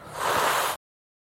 Звуки шипения кота
Ревнивое шипение кота